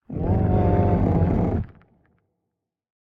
Minecraft Version Minecraft Version latest Latest Release | Latest Snapshot latest / assets / minecraft / sounds / mob / warden / ambient_5.ogg Compare With Compare With Latest Release | Latest Snapshot